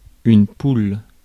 Ääntäminen
France (Paris): IPA: [yn pul]